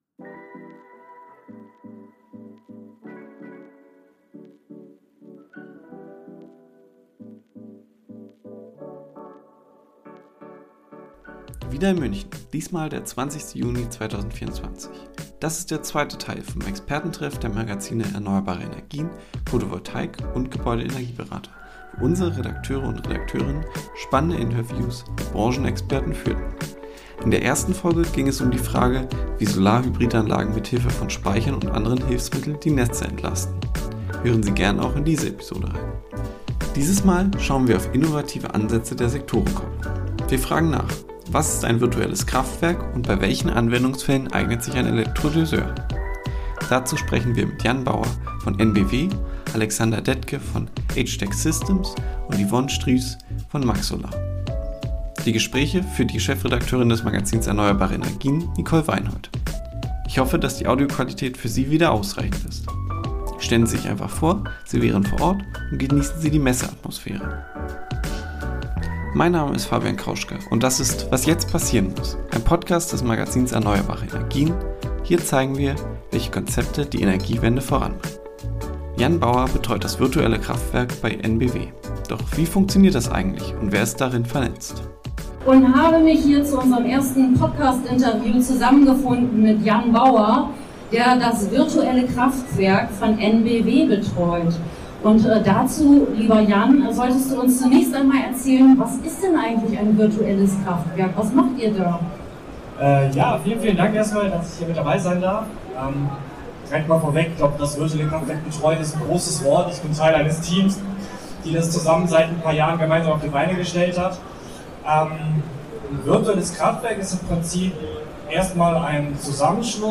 Ich hoffe, dass die Audioqualität für Sie wieder ausreichend ist. Stellen Sie sich einfach vor, Sie wären vor Ort und genießen Sie die Messe-Atmosphäre.